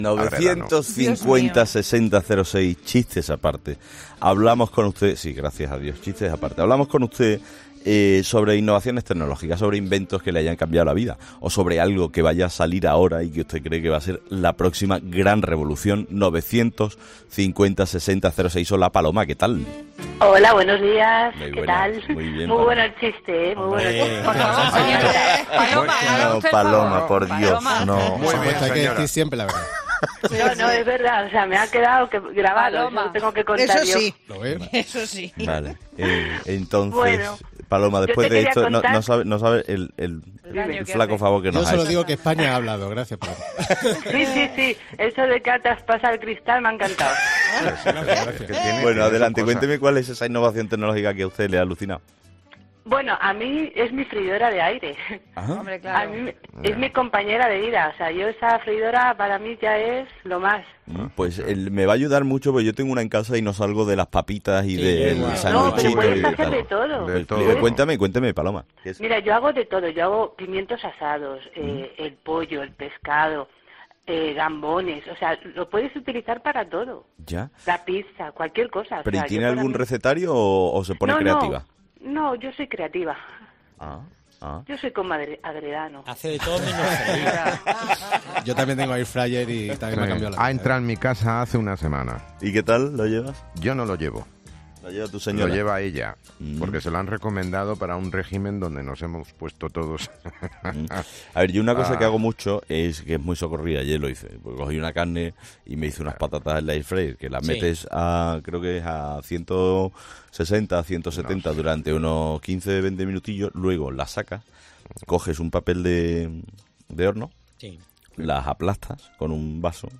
Hablamos con nuestros 'fósforos' sobre aquellos inventos que les han cambiado la vida